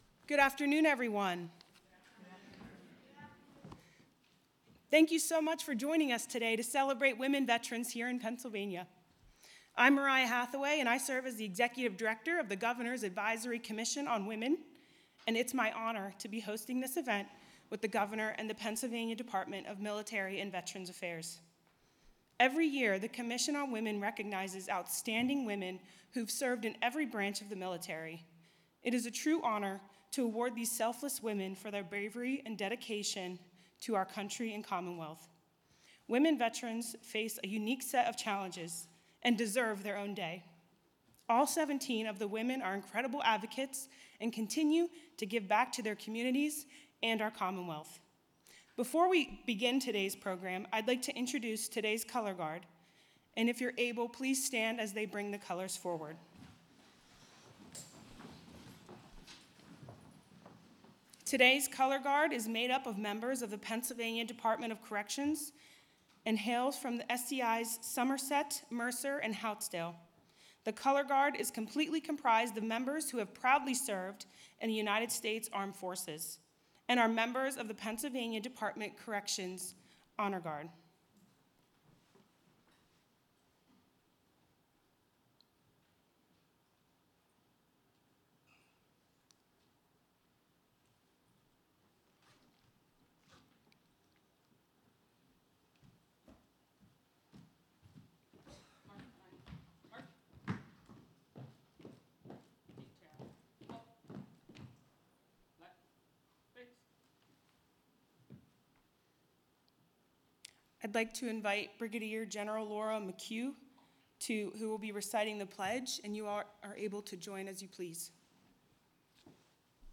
Shapiro Administration Honors Outstanding Women Veterans During Women Veterans Day